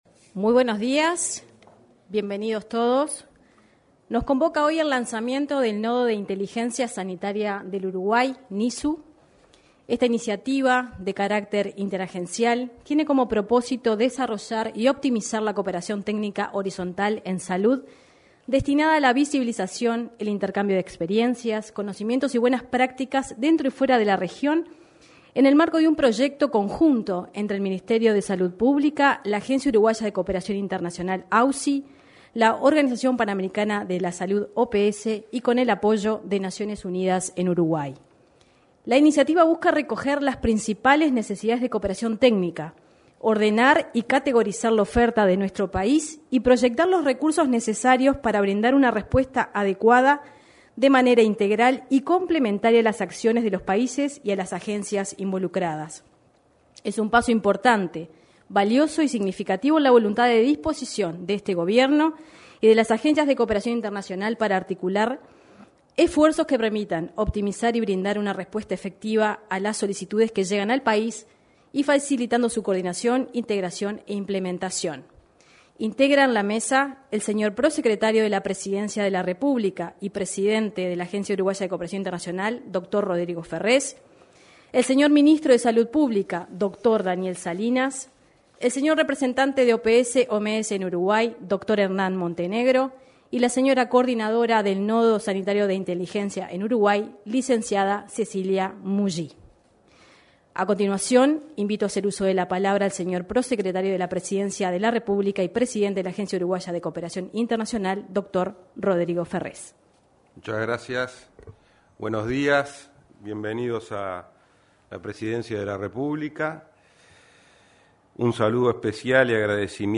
Lanzamiento del Nodo de Inteligencia Sanitaria de Uruguay 17/02/2023 Compartir Facebook X Copiar enlace WhatsApp LinkedIn Este jueves 16, se realizó el lanzamiento del Nodo de Inteligencia Sanitaria de Uruguay (NISU).